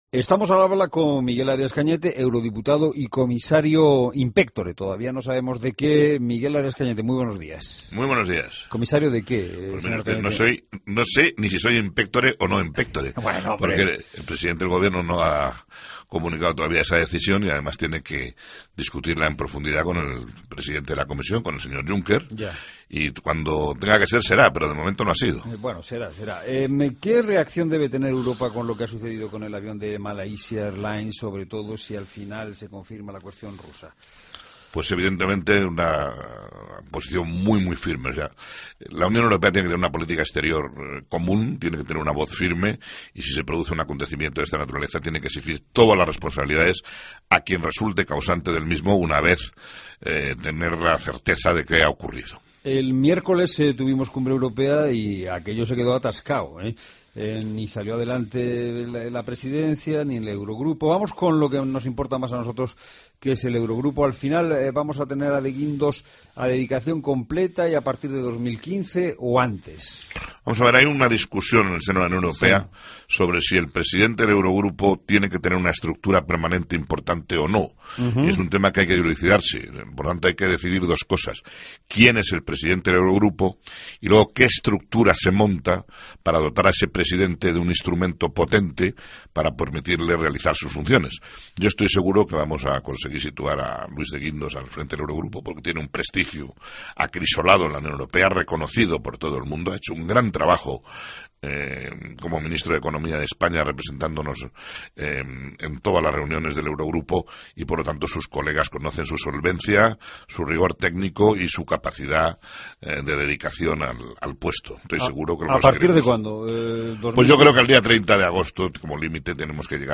Entrevista a Miguel Arias Cañete en La Mañana Fin de Semana